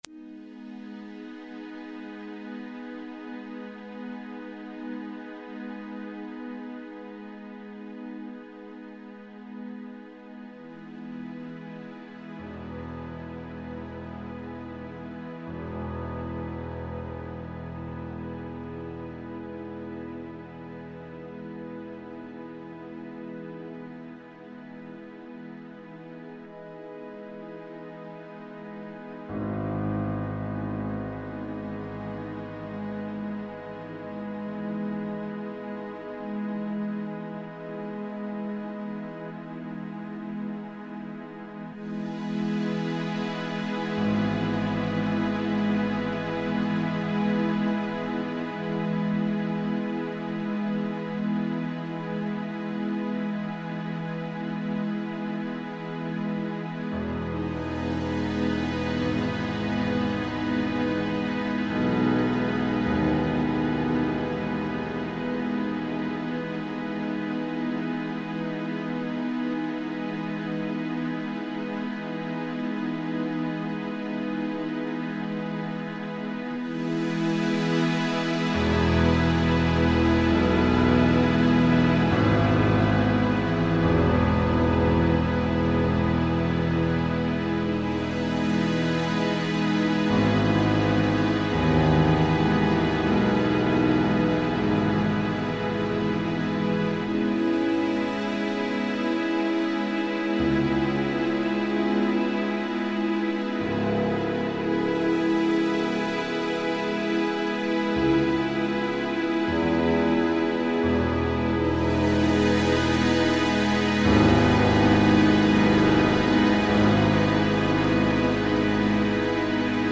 File under: Ambient / Avantgarde